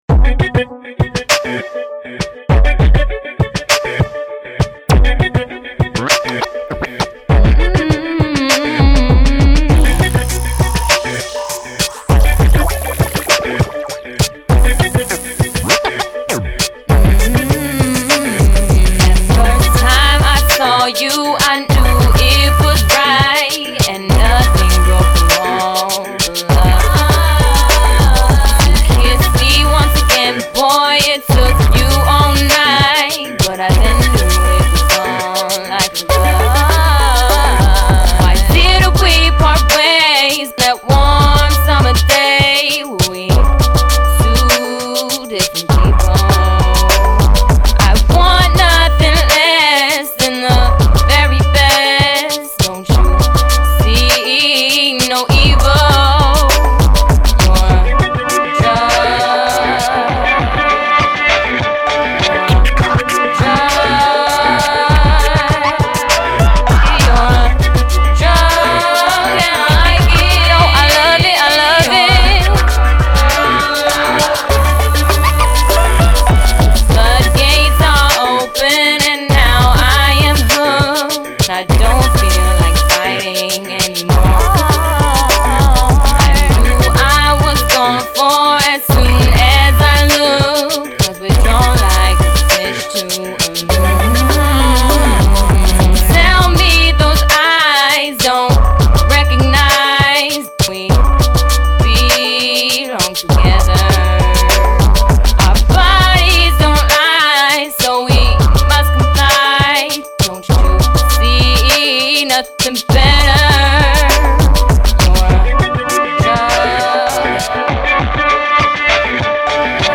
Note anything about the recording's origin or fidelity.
Recorded at Ground Zero Studios and Seattle Chop Shop